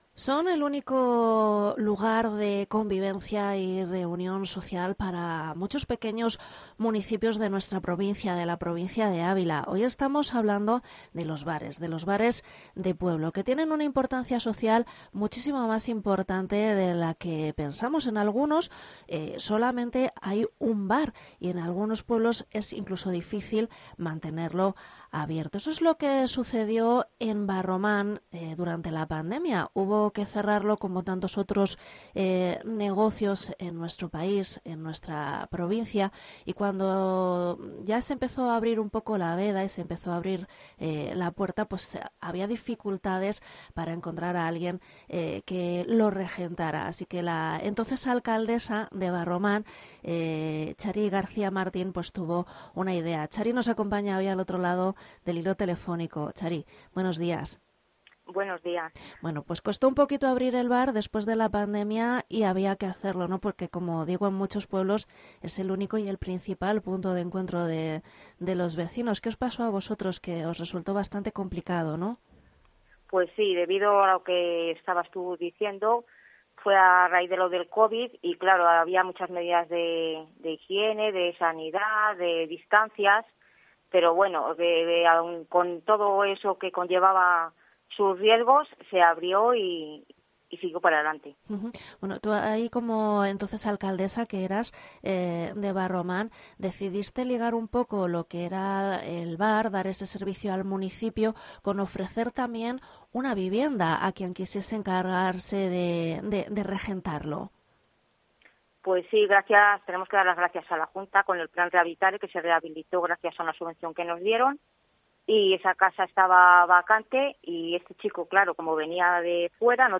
AUDIO: Entrevista Chari García, ex alcaldesa de Barroman